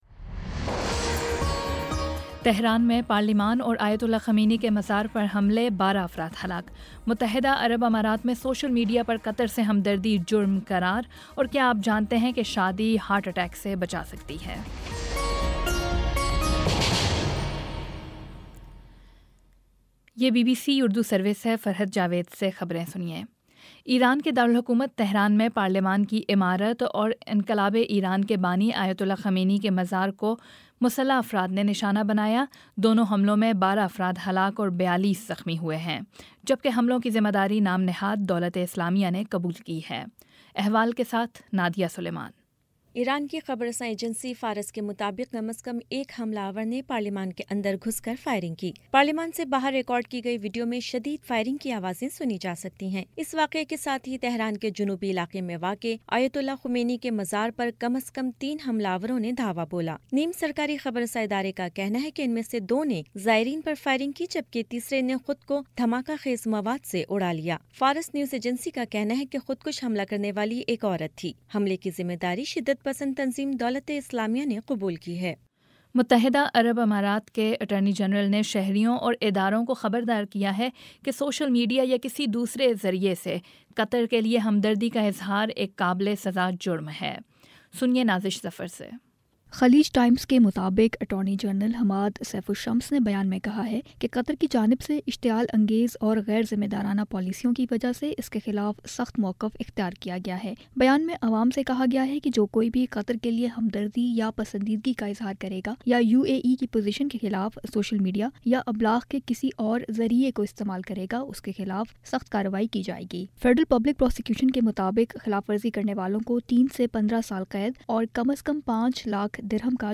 جون 07 : شام سات بجے کا نیوز بُلیٹن